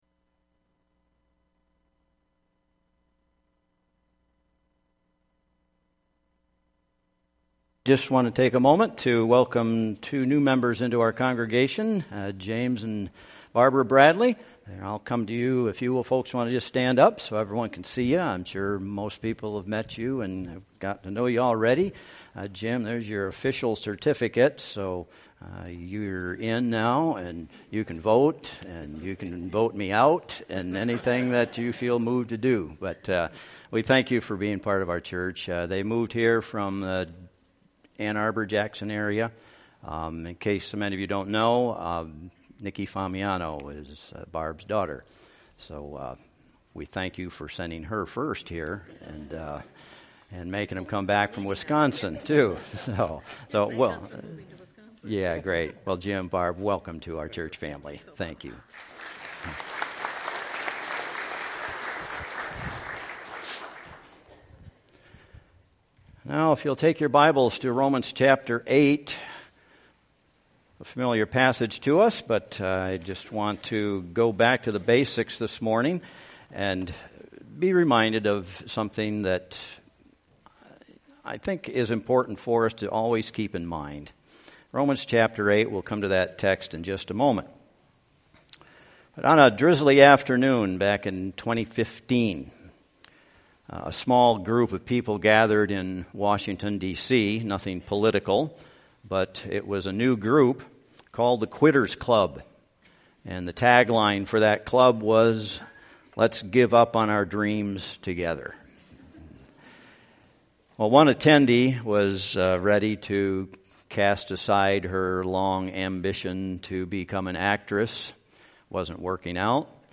Passage: Romans 8:31-37 Service Type: Worship Service